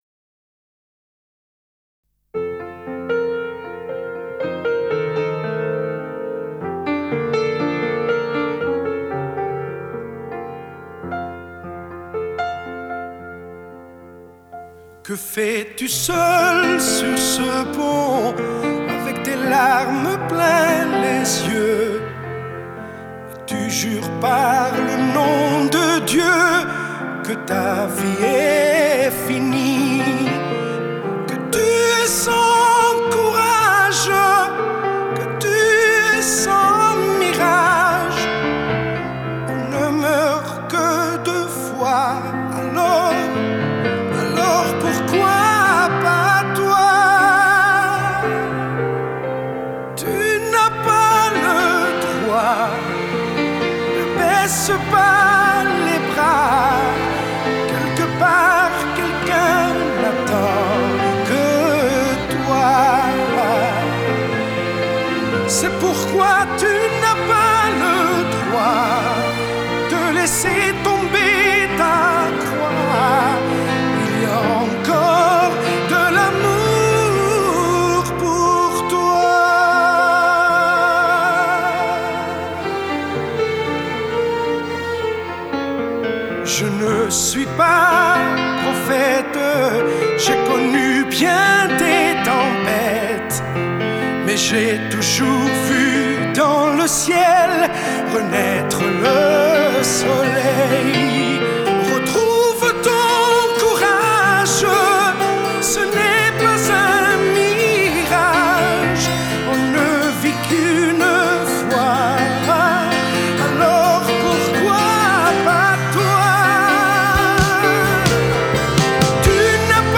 (там и звук получше)